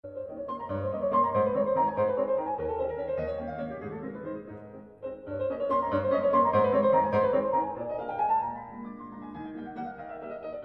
Virtual ambisonic rendering corresponding to the movies in Table 7.
Train yourself with the reference file first (single source r=0%) if you have not already done so, in order to really imagine that the source is drawing a circular (anti-clock wise), horizontal trajectory around you.